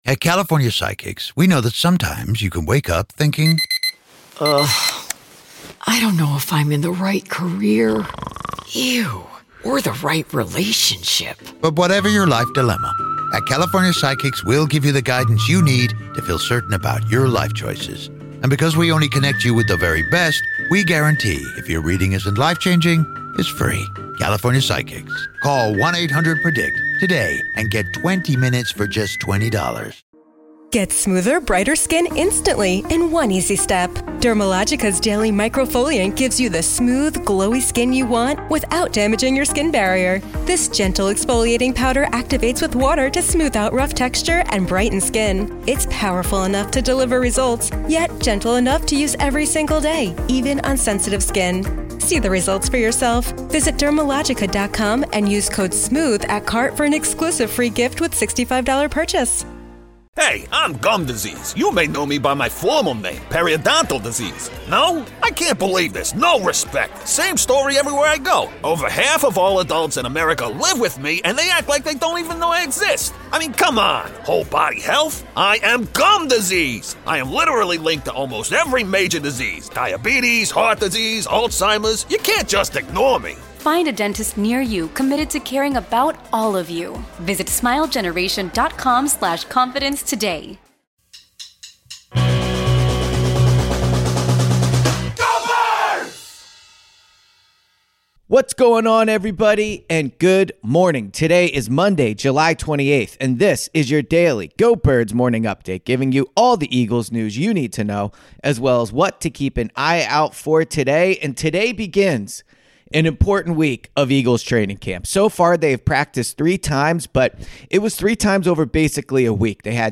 The 94WIP Morning Show delivers everything Philly sports fans crave — passionate takes, smart analysis, and the kind of raw, authentic energy that defines the city.